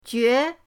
jue2.mp3